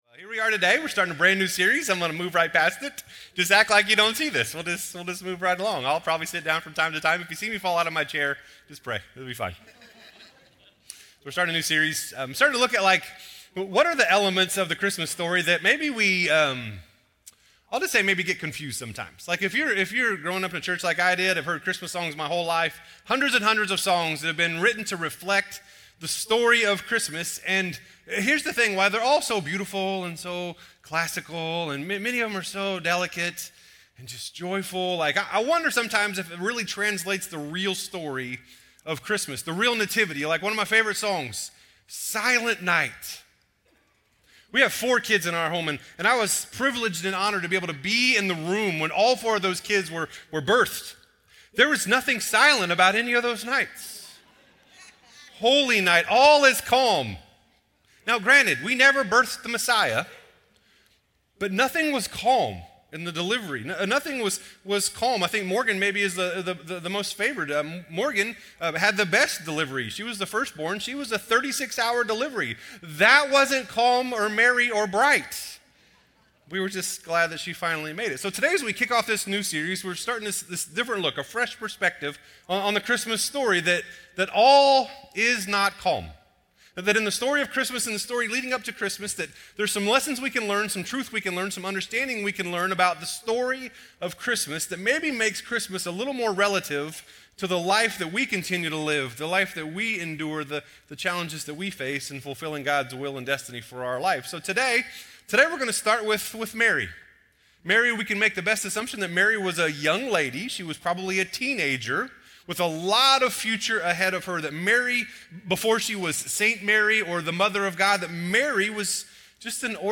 Crossroads Community Church - Audio Sermons 2022-11-27 - Why Mary?